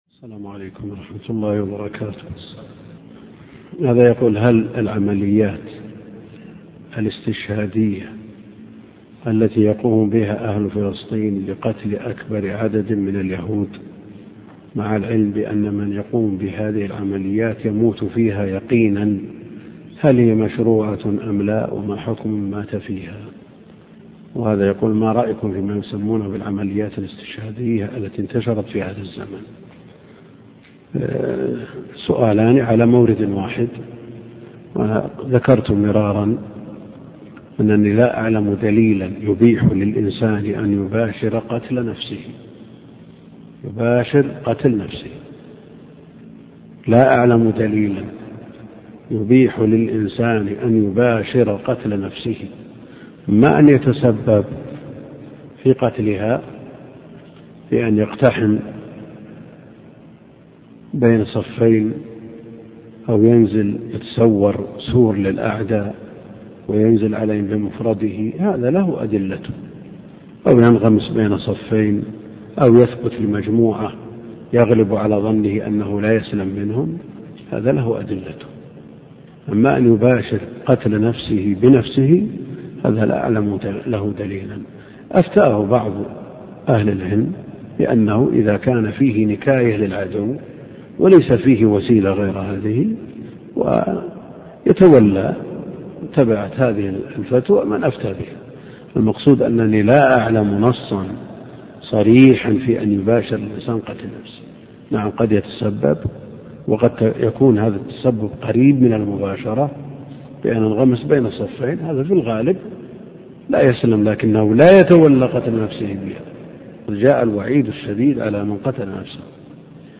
الدرس (18) شرح سنن ابن ماجه - الدكتور عبد الكريم الخضير